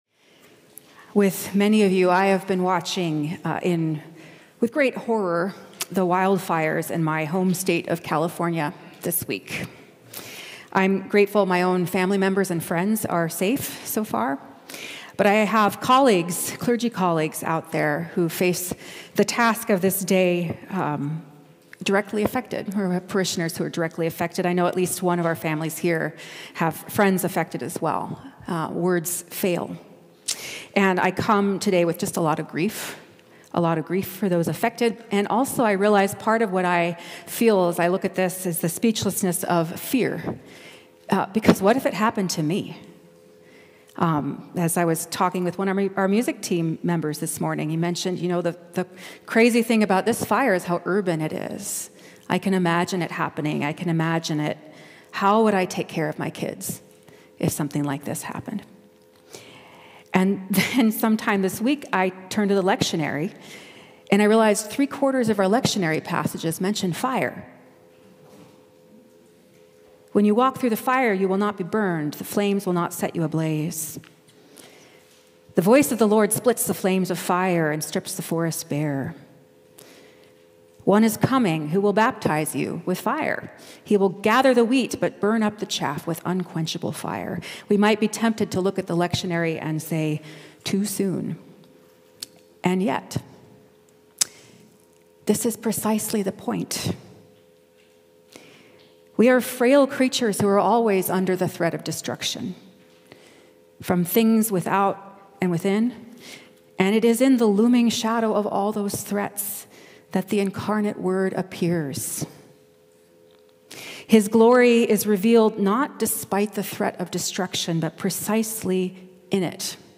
This podcast features recordings from Church of the Redeemer in Highwood, Illinois.